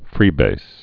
(frēbās)